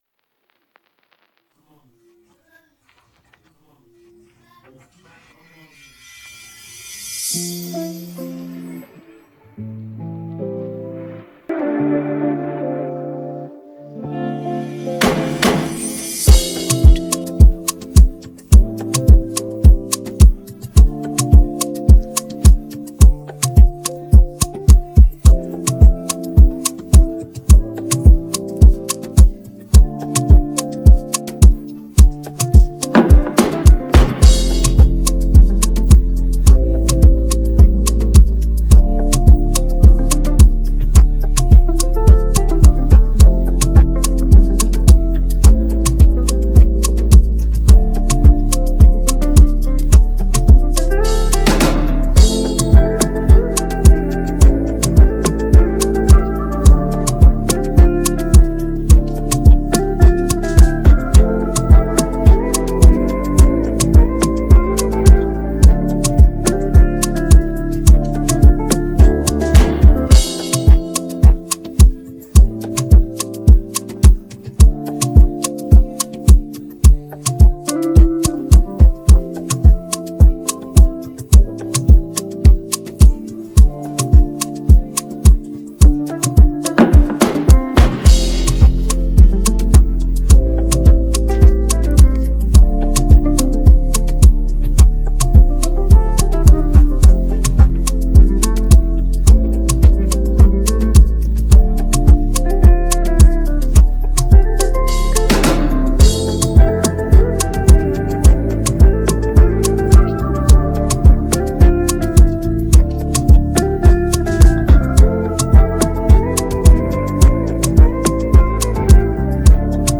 Afro popAfrobeats